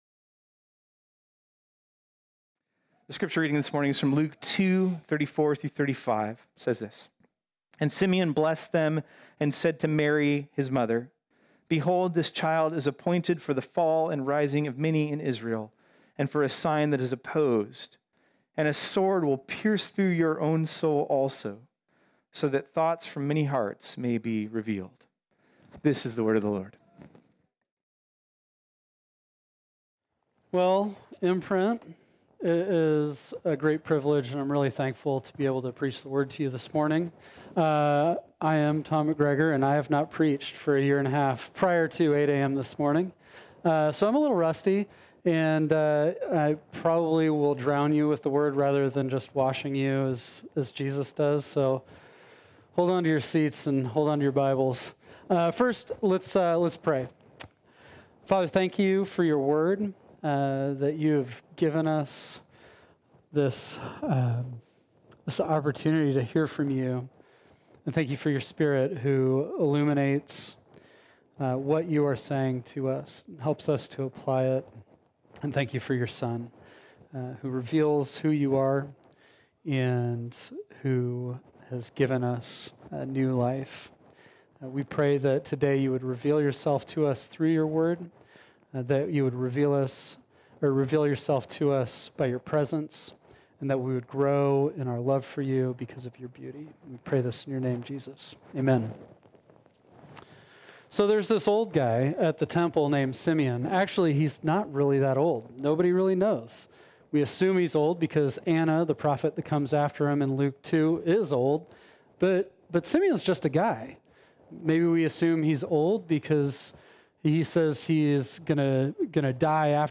This sermon was originally preached on Sunday, December 27, 2020.